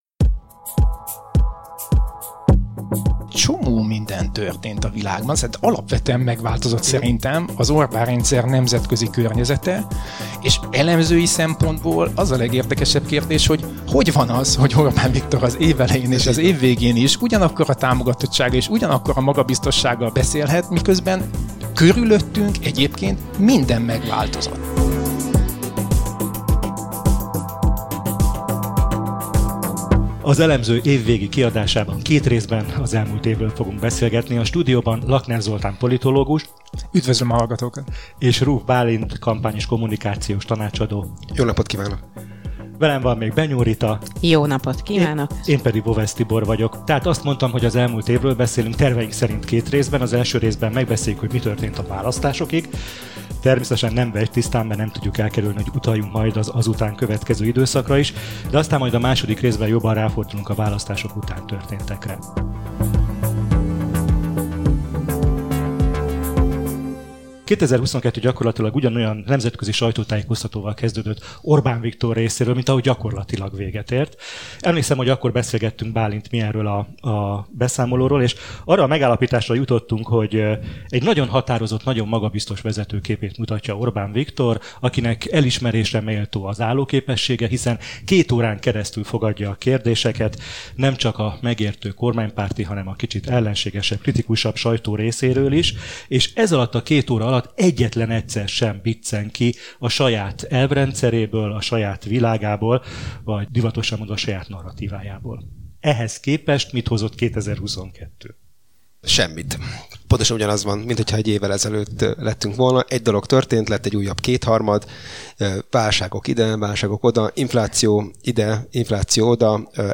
Hiába tűnik színjátéknak a választás egy autokrata rendszer legitimálásához, az ellenzék mégsem hivatkozhat erre eredménytelenségét magyarázva – hallható a beszélgetés első részében. Most a választásokig vezető utat tekintjük át, holnap az április óta eltelt időszakkal folytatjuk.